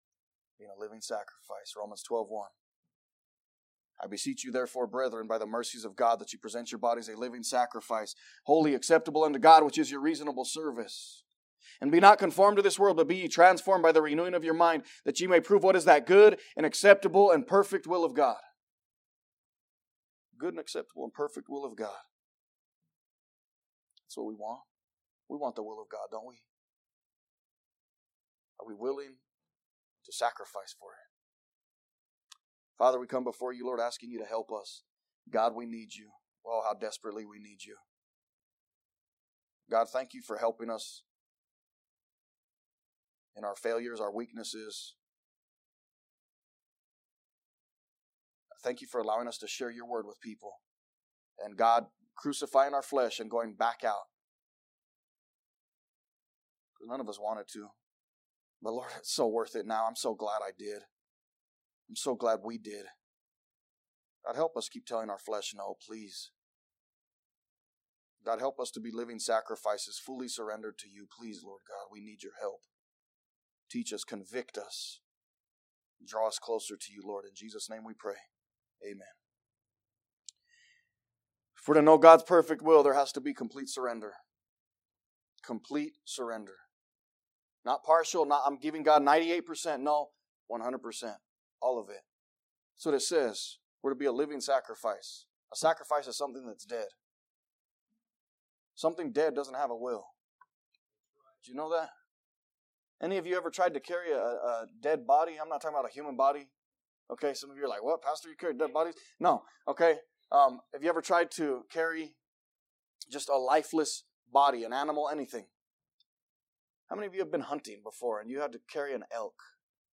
A message from the series "The Hall of Flaw."